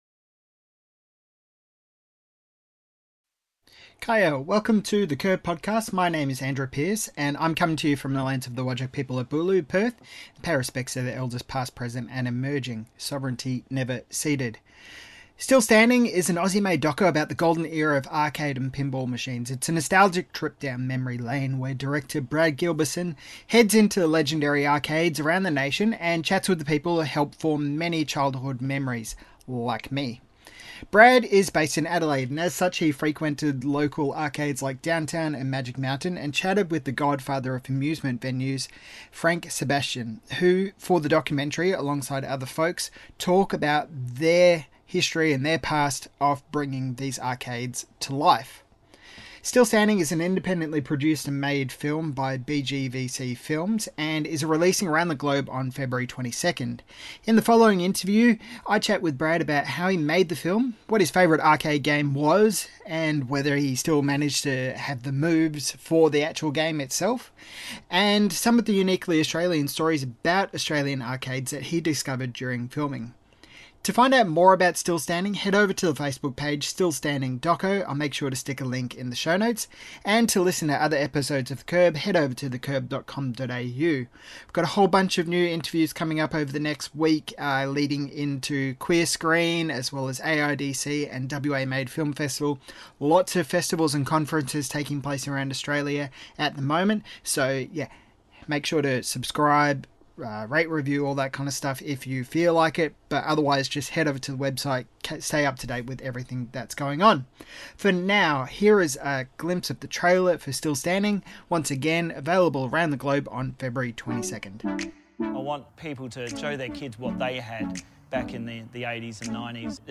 Interview - The Curb